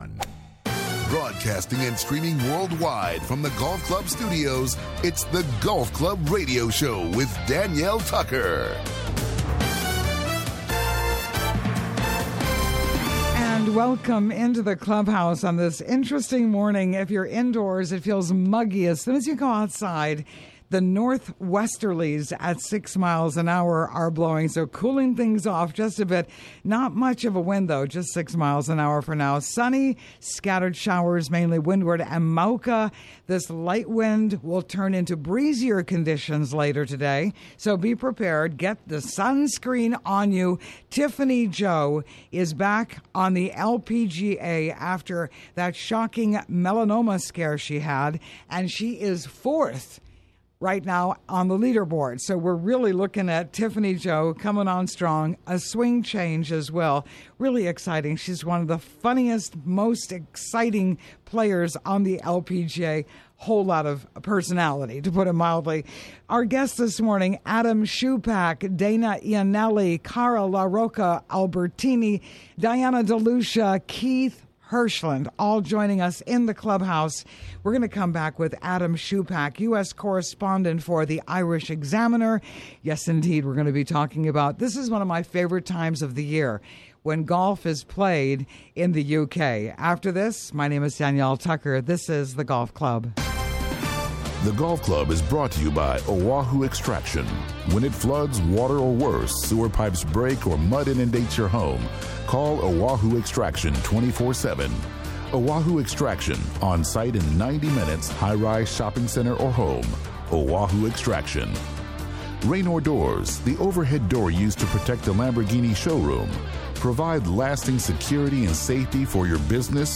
Radio Show